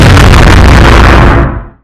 tbuster_explode.mp3